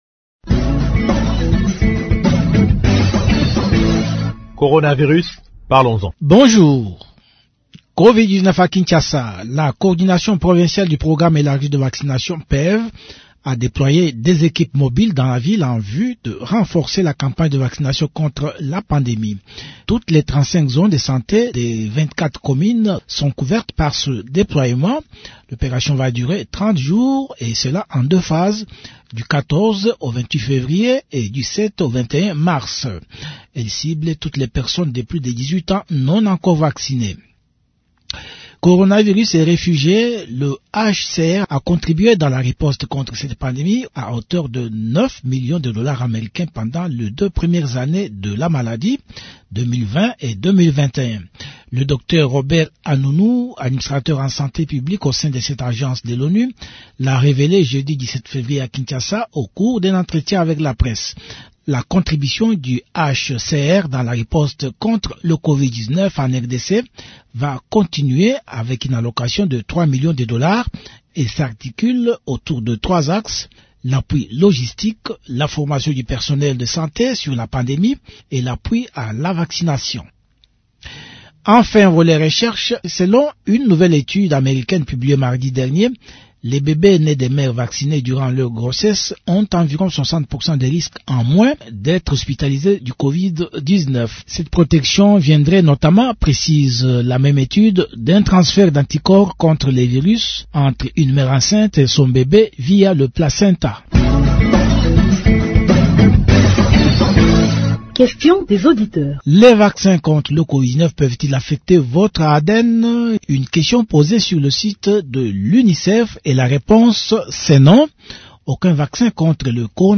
Promo